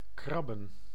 Ääntäminen
US : IPA : [skɹætʃ]